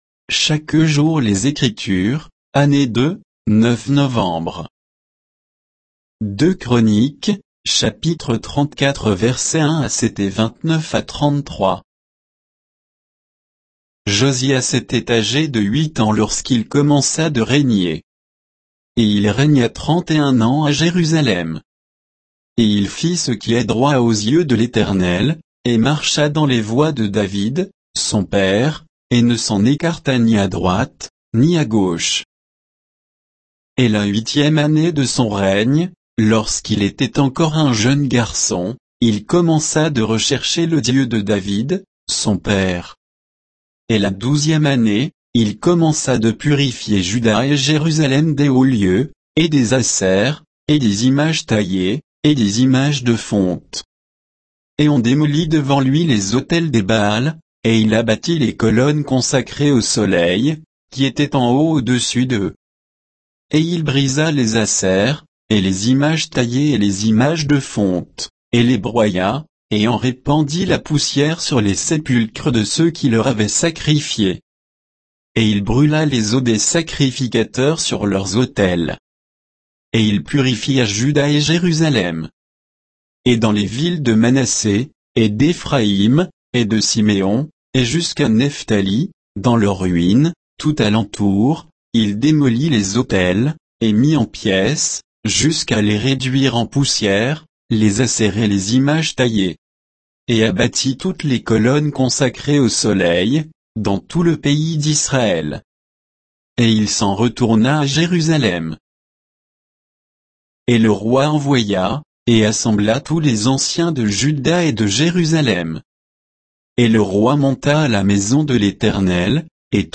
Méditation quoditienne de Chaque jour les Écritures sur 2 Chroniques 34, 1 à 7, 29 à 33